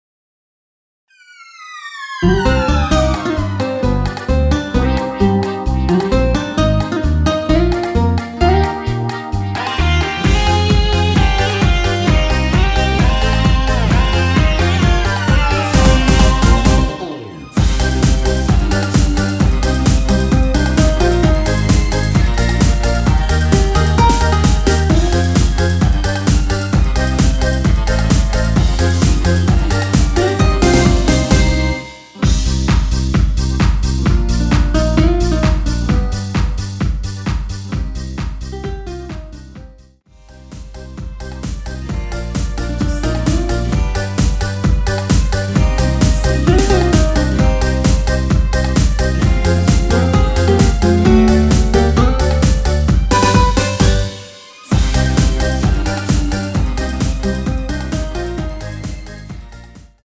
원키에서(-1)내린 코러스 포함된 MR입니다.
Cm
앞부분30초, 뒷부분30초씩 편집해서 올려 드리고 있습니다.
중간에 음이 끈어지고 다시 나오는 이유는